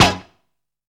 ZAP HORN.wav